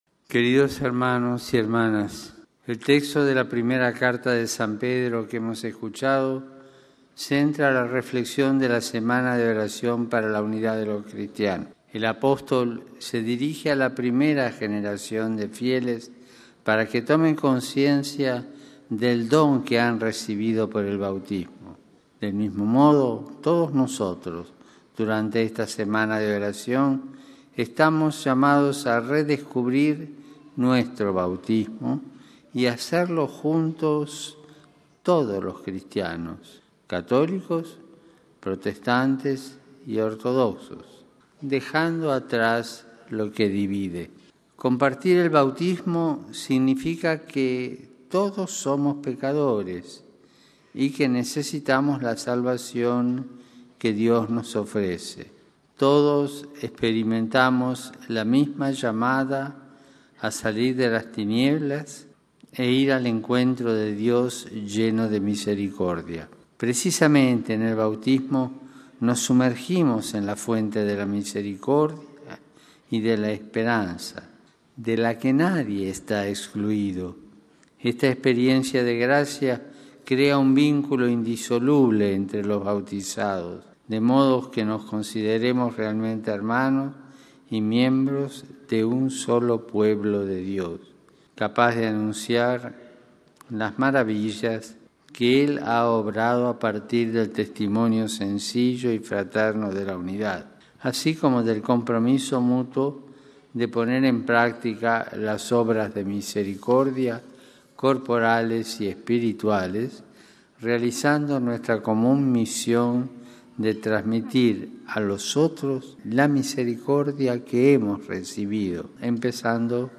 (RV).- En su audiencia general del tercer miércoles de enero, celebrada en el Aula Pablo VI de la Ciudad del Vaticano, el Papa Francisco propuso a la atención de los fieles el tema de la Semana de Oración por la Unidad de los Cristianos que en numerosos países se celebra del 18 al 25 de enero.
Texto y audio de la catequesis que el Santo Padre Francisco pronunció en nuestro idioma: